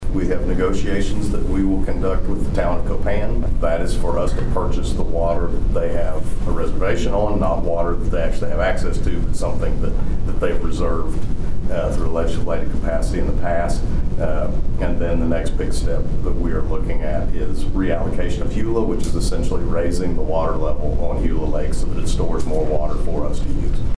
During this week's Bartlesville city council meeting, City Manager Mike Bailey shared a water supply update following the beneficial rains we received during the past week.